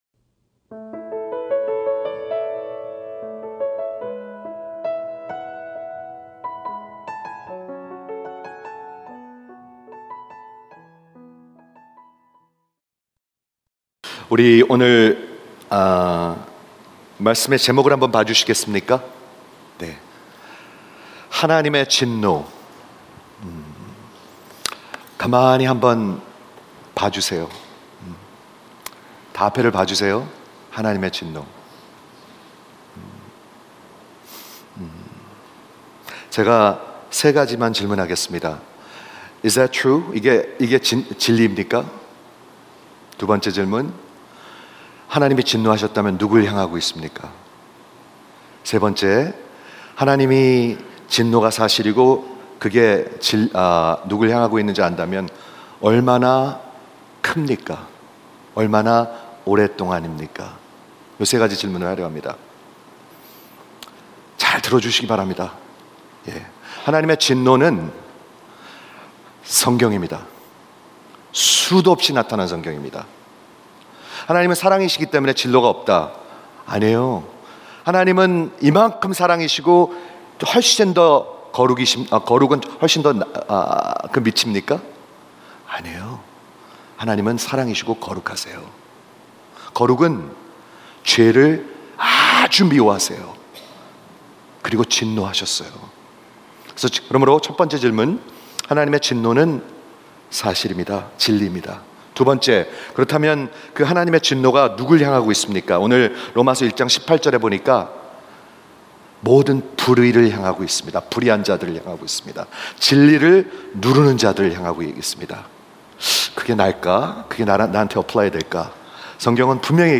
Christianity Expounded (3) 로마서 설교 시리즈